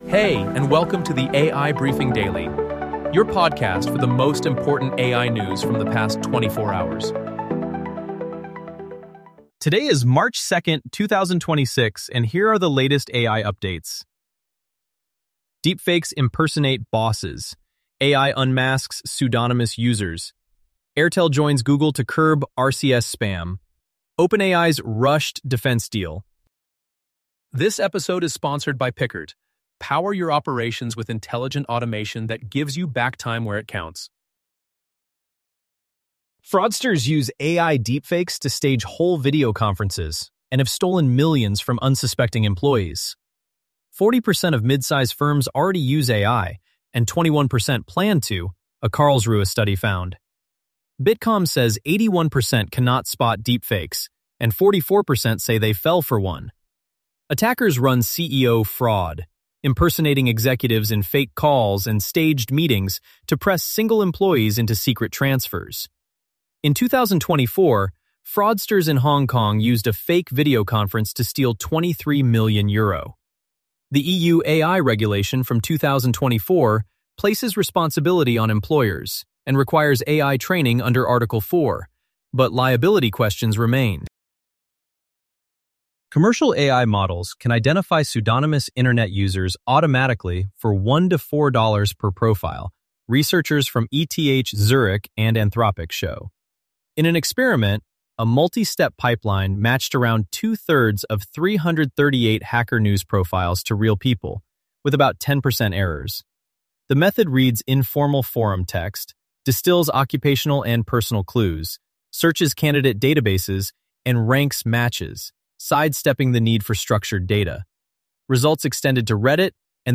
Unique: Our podcast is 100% AI-generated - from research to production to upload.